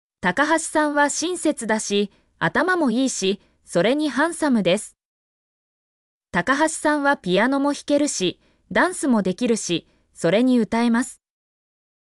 mp3-output-ttsfreedotcom-39_m1NCVED7.mp3